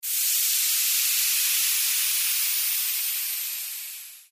Processed Air Release